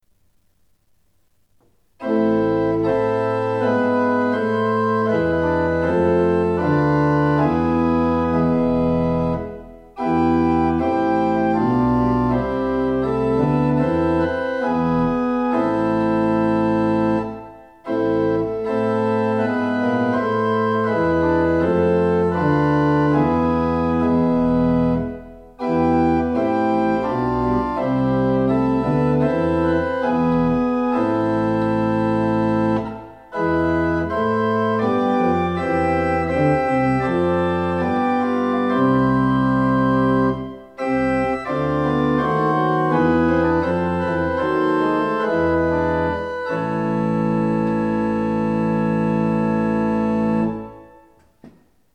Orgelklang
an der Andresen-Orgel der Martin-Luther-Gemeinde Bad Schwartau
Orgelklang2_BWV93.mp3